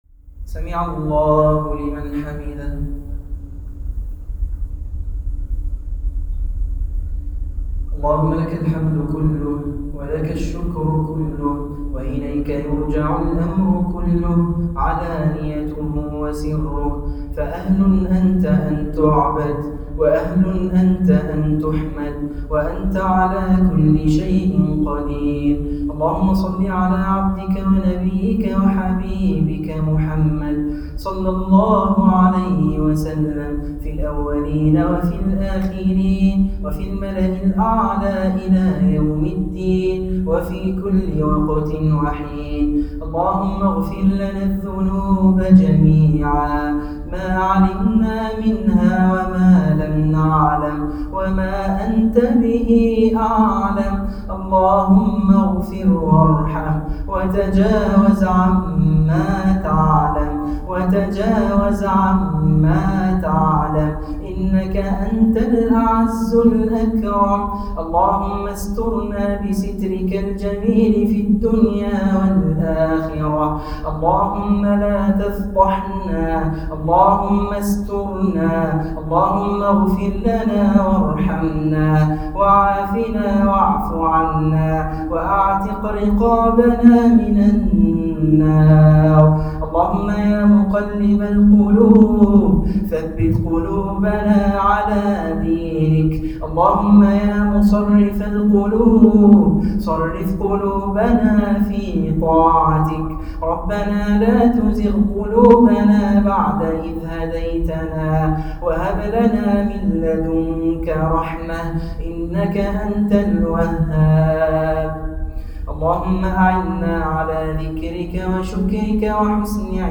أدعية وأذكار
دعاء خاشع ليلة 28 رمضان 1438هـ
تسجيل لدعاء خاشع ومؤثر